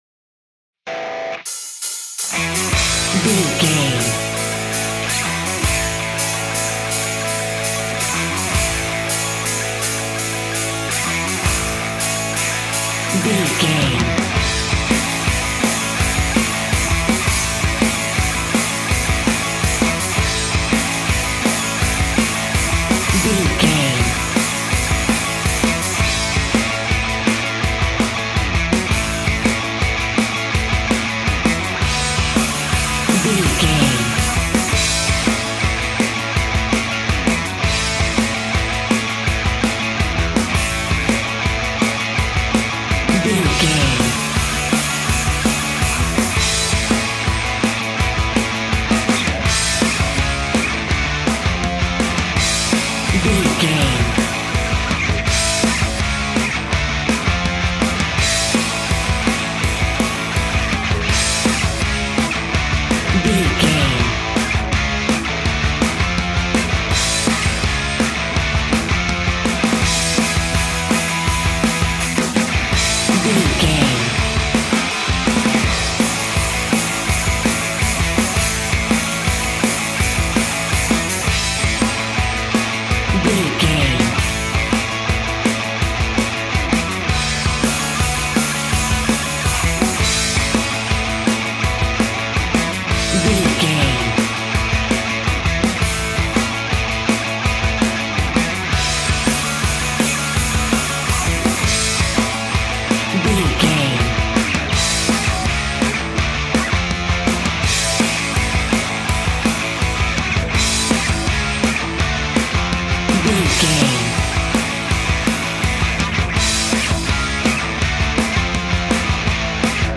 Ionian/Major
pop rock
indie pop
fun
energetic
uplifting
instrumentals
upbeat
rocking
groovy
guitars
bass
drums
piano
organ